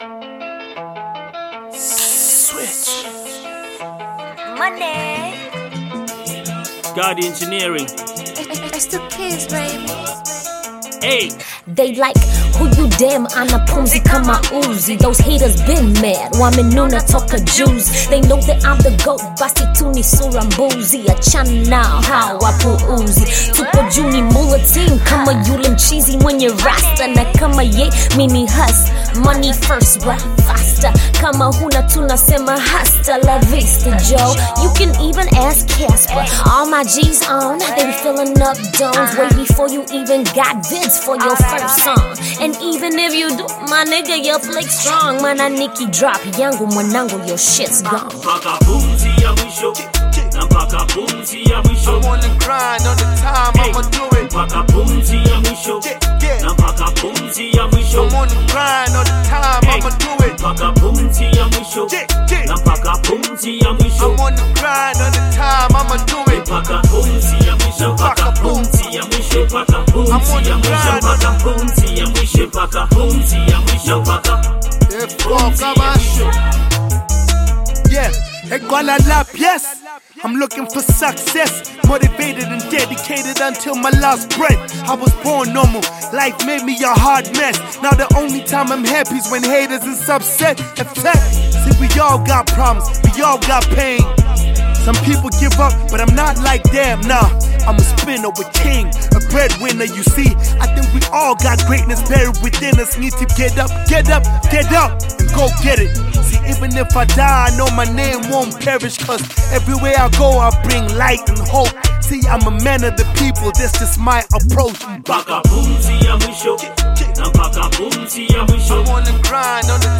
Tanzania Pop
South African Rapper